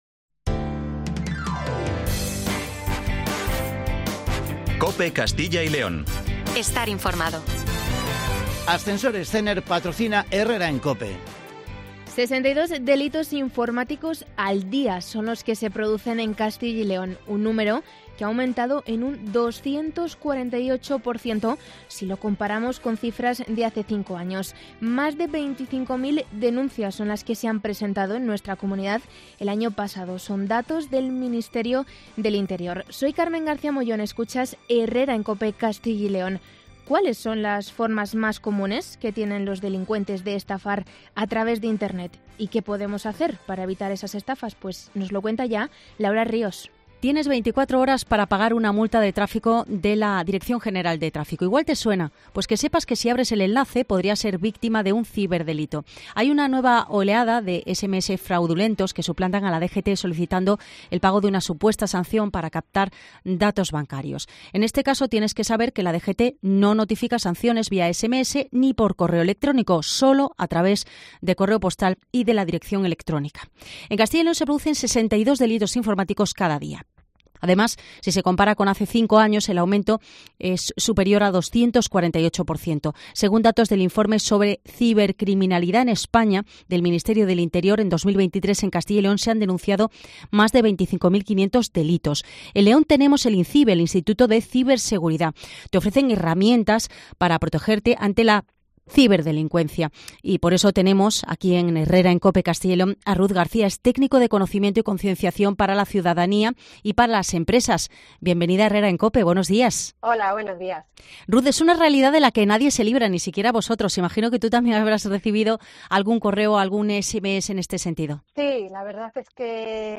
Desde el INCIBE nos alertan del crecimiento de la cibercriminalidad y nos ofrecen herramientas para protegerte. Hablamos con